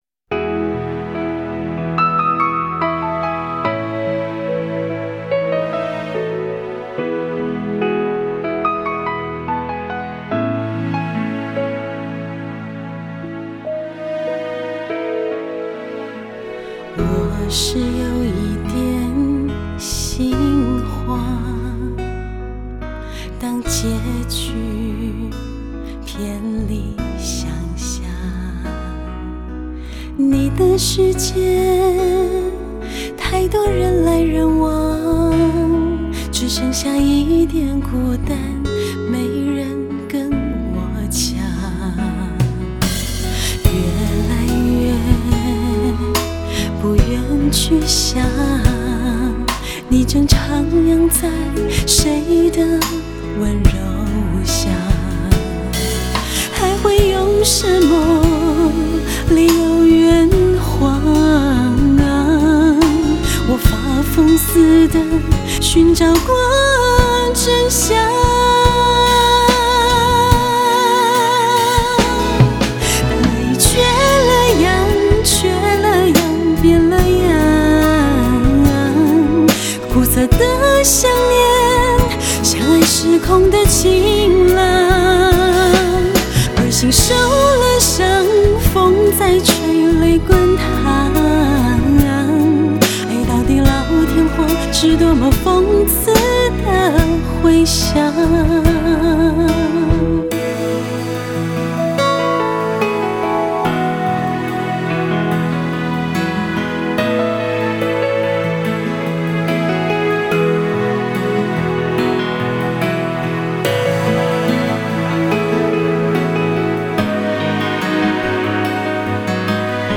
收音机里缓缓飘扬着一个声音带着清澈柔美，字句铿锵有力的歌姬于天空的另一端奔放地吟唱着的旋律。